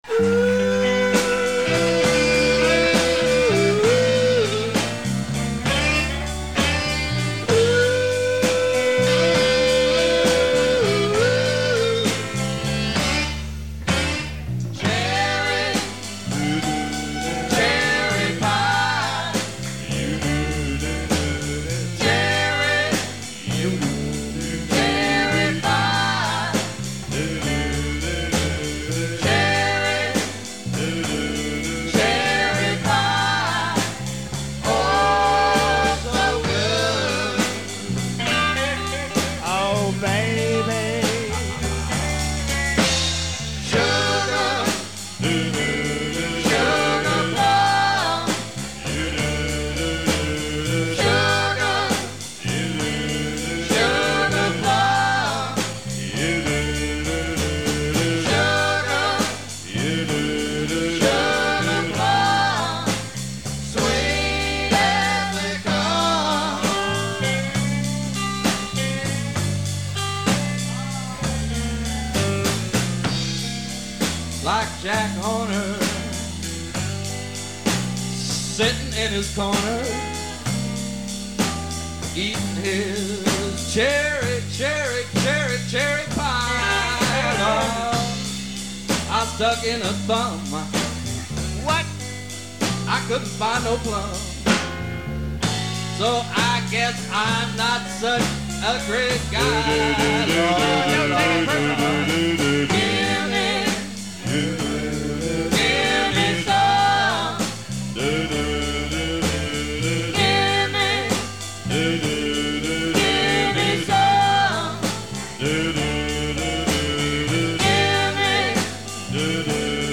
with wonderful backup harmonies and saxophone.